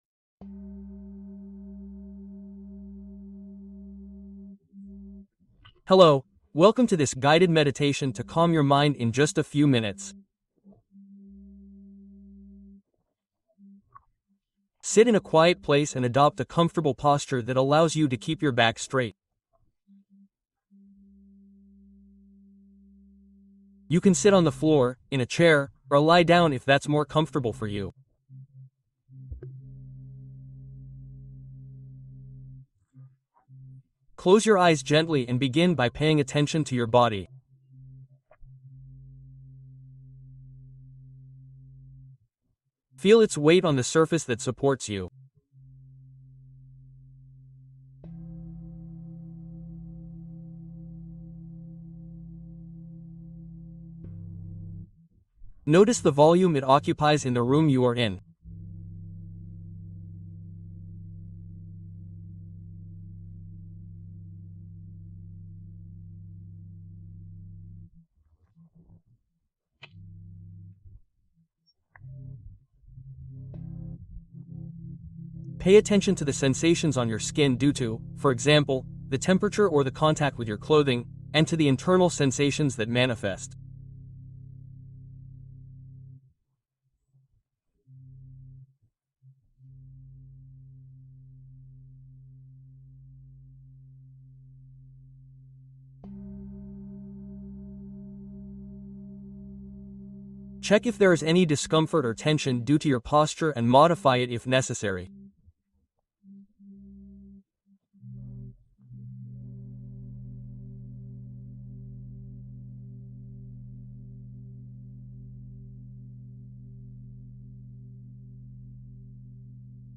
Mindfulness Guiado para Silenciar Pensamientos Recurrentes